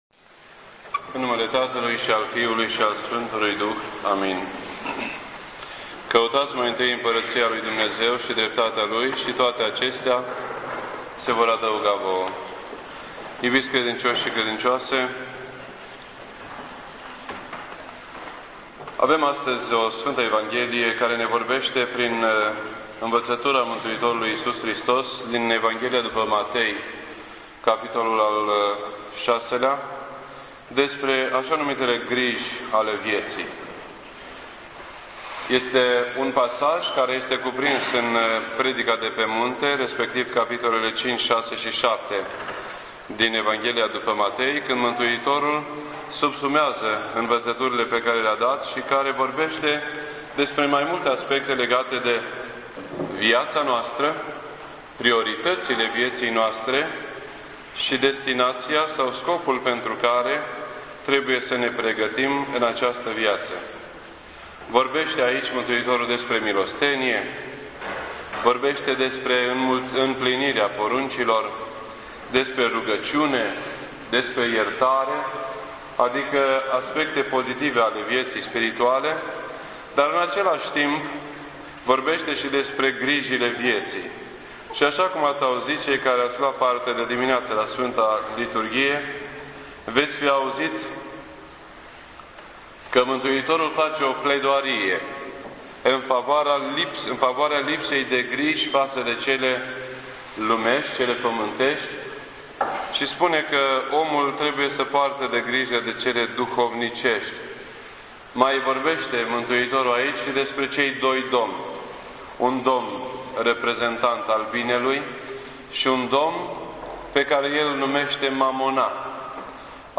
This entry was posted on Sunday, July 6th, 2008 at 9:39 AM and is filed under Predici ortodoxe in format audio.